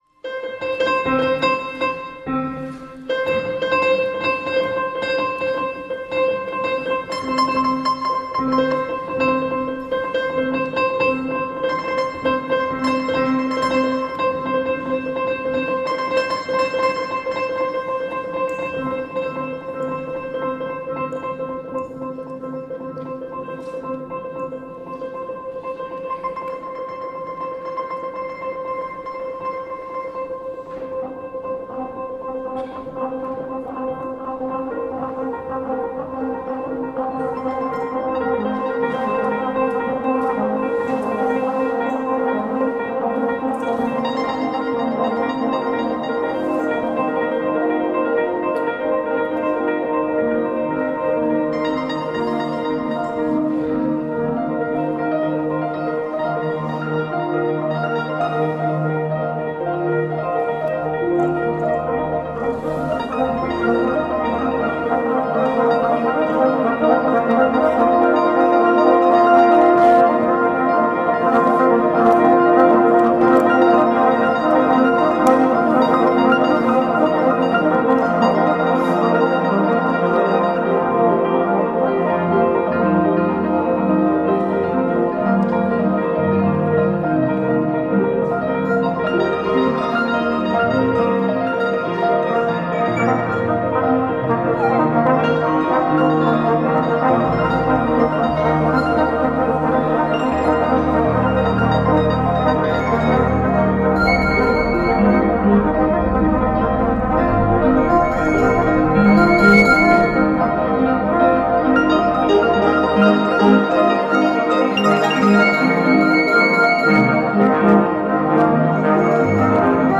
彼女はアコーディオンを操り、ディジュリドゥ、おもちゃ、トロンボーン、ピアノと共に深淵な重奏持続音を奏で続ける名演。